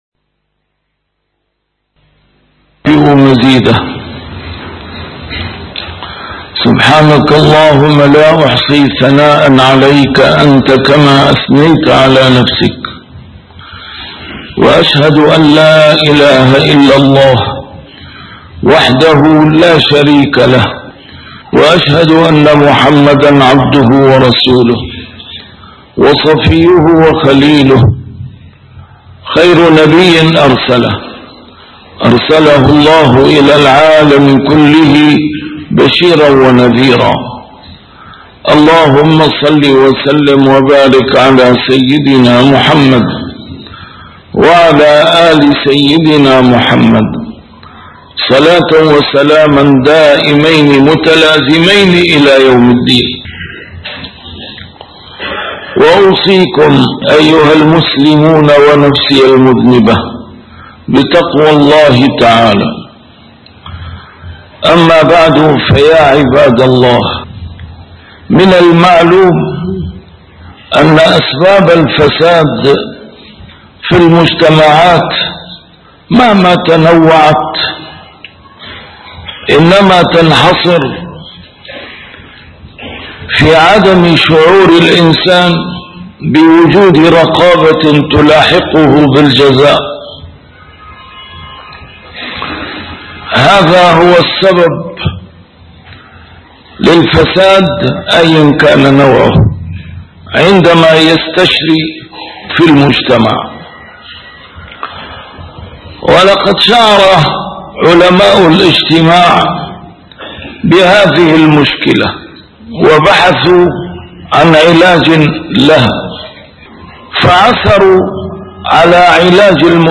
نسيم الشام › A MARTYR SCHOLAR: IMAM MUHAMMAD SAEED RAMADAN AL-BOUTI - الخطب - منهج امتلاخ الفساد والإفساد من المجتمع